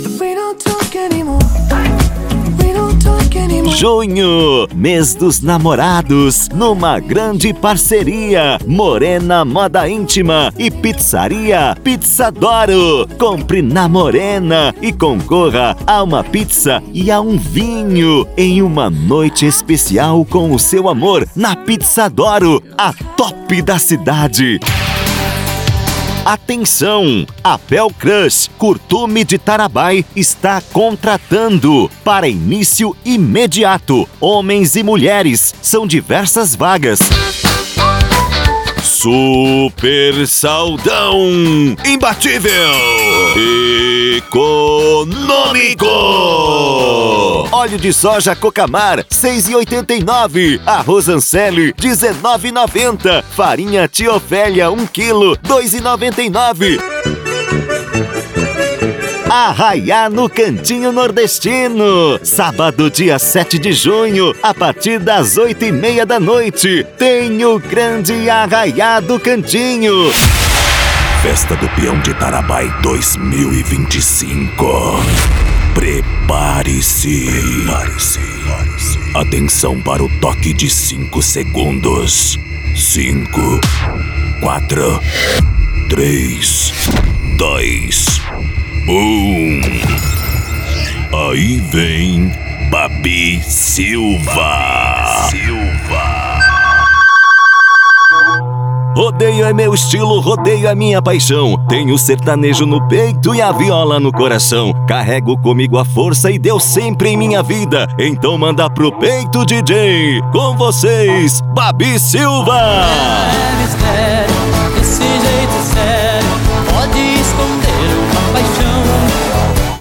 Spot Comercial
Padrão
Impacto
Animada